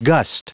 Prononciation du mot gust en anglais (fichier audio)
Prononciation du mot : gust